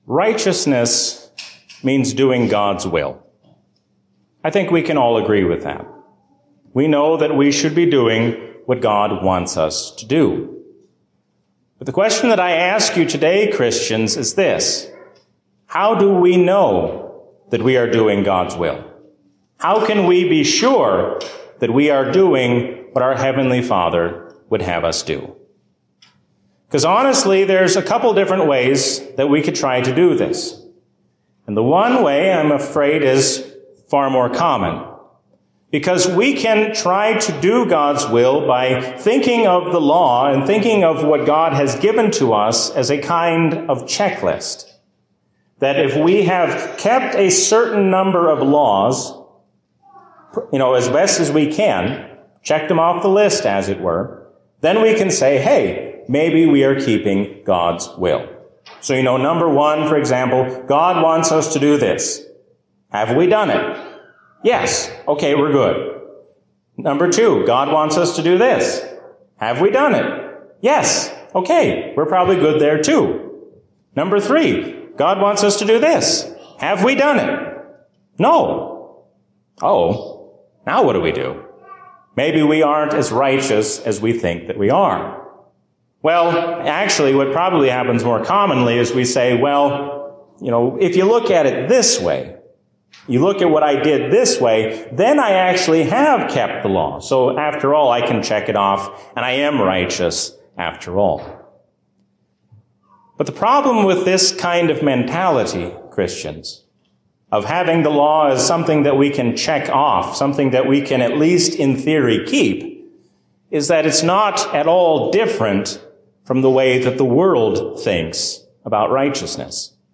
A sermon from the season "Trinity 2023." Listen to Jesus and do what He says, because He is your loving Lord.